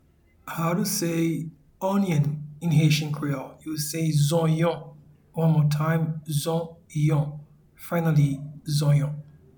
Pronunciation and Transcript:
Onion-in-Haitian-Creole-Zonyon.mp3